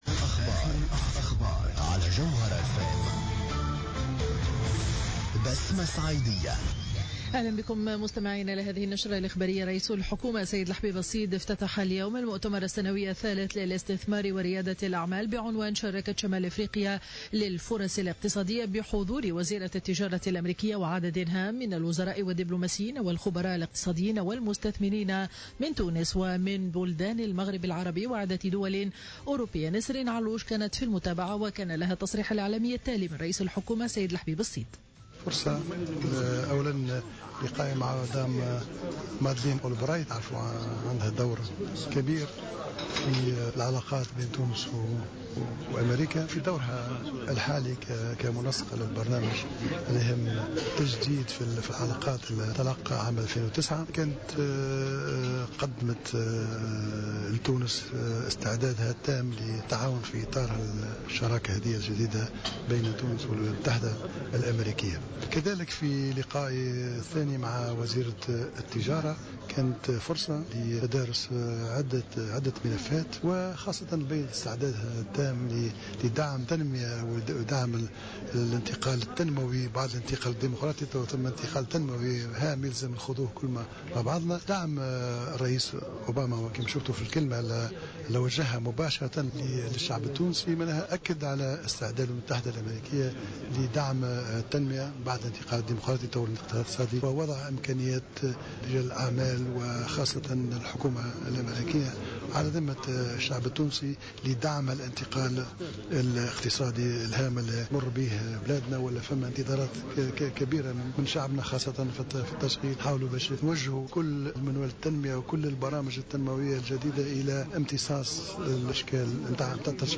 نشرة الأخبار منتصف النهار ليوم الخميس 05 مارس 2015